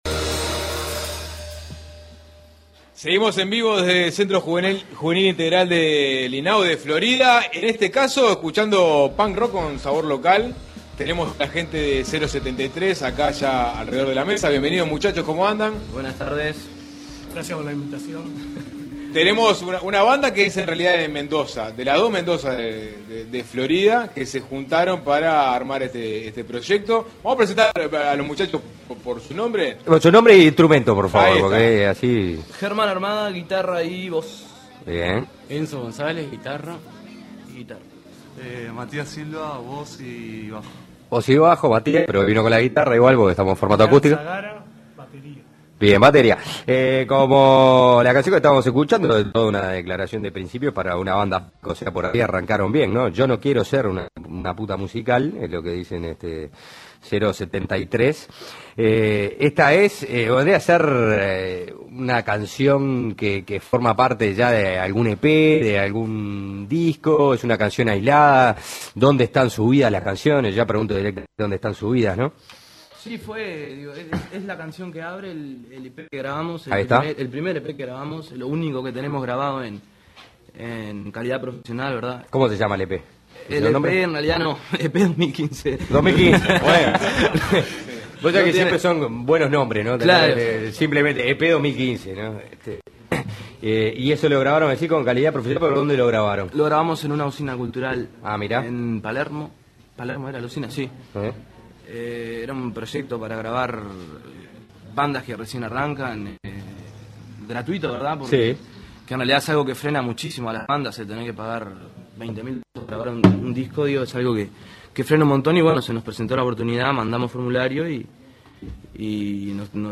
Zero 73: punk rock